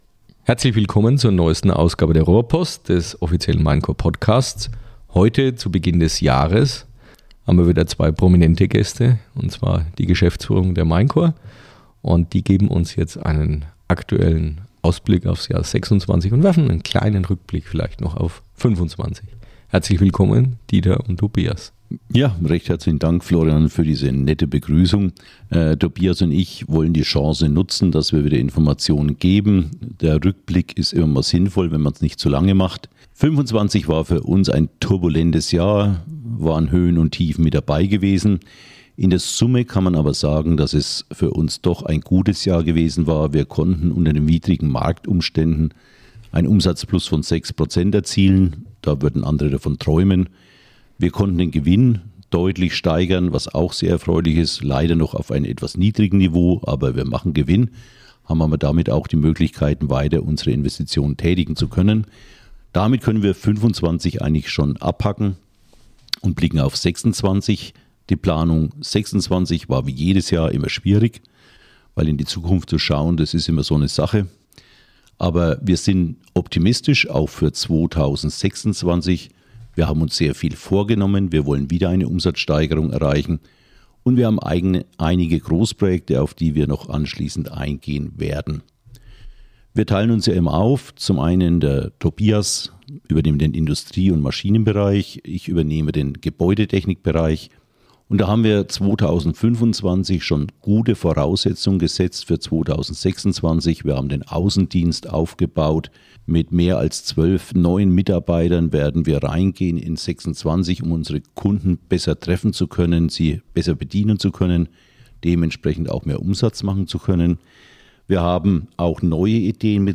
die Geschäftsleitung zu Gast im Podcast